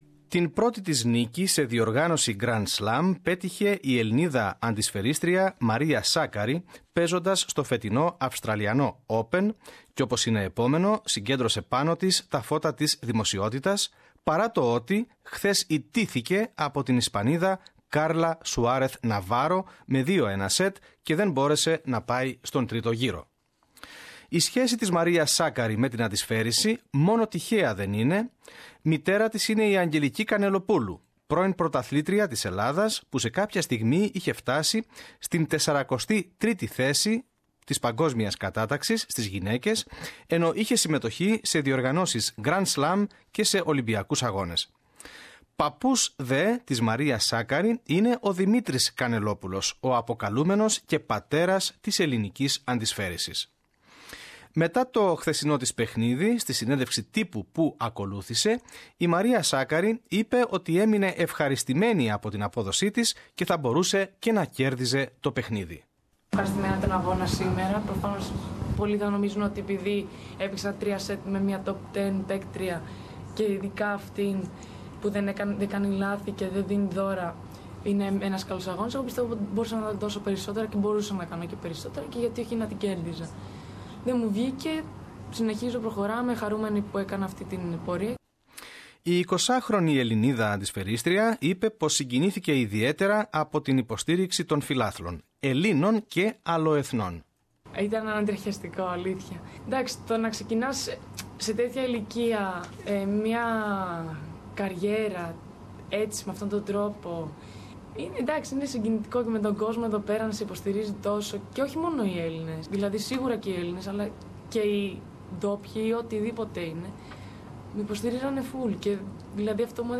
After yesterdays match, at the press conference, she answered to SBS Radios Greek Language program questions.